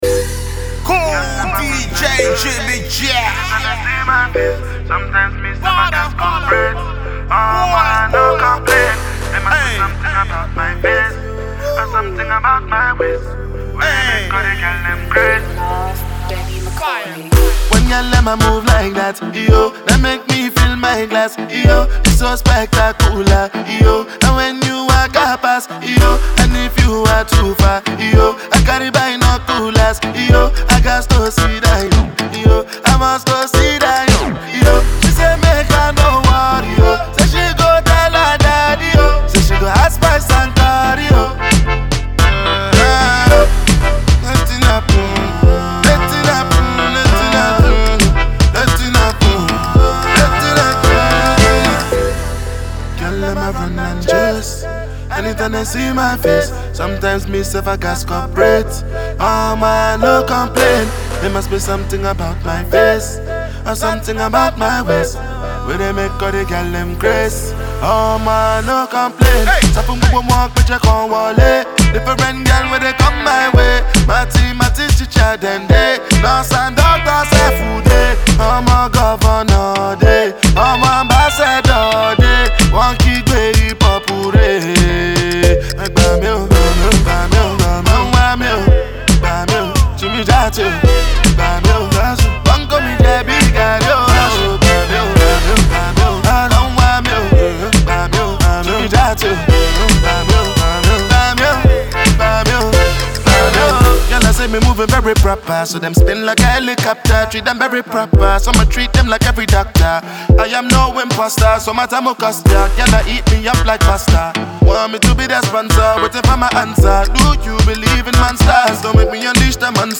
heavy tune